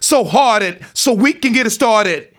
RAPHRASE13.wav